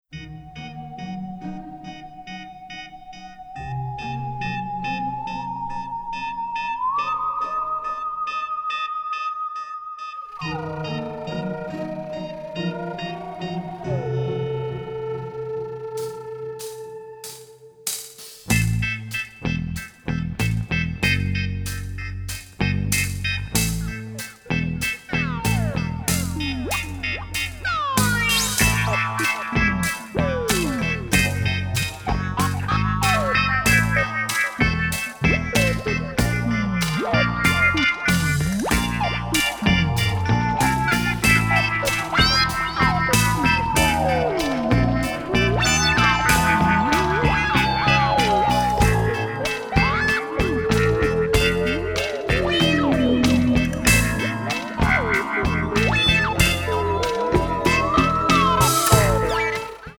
dynamic score, written in a symphonic jazz style
Recorded in London